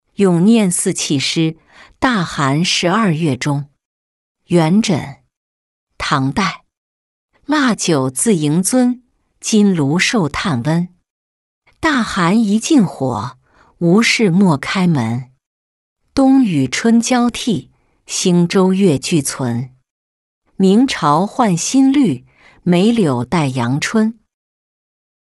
咏廿四气诗·大寒十二月中-音频朗读